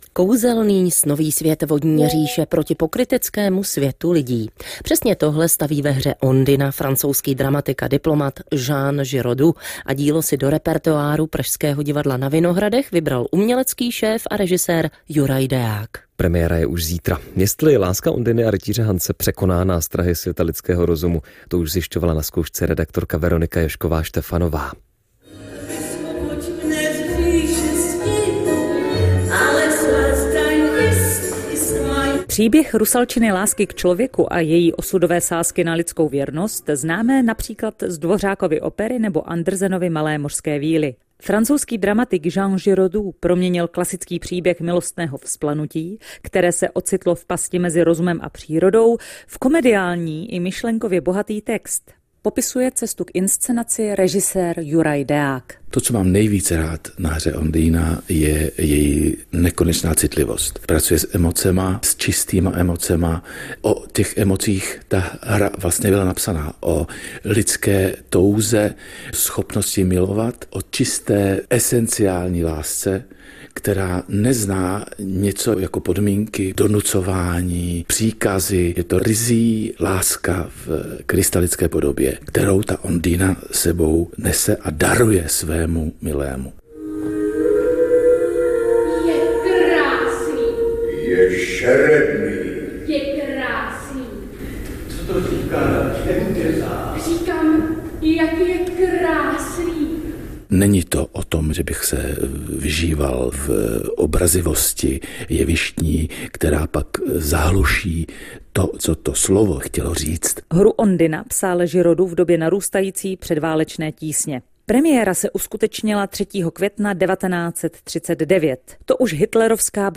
Záznam je z pořadu Mozaika na ČRo Vltava.
reportazOndina.mp3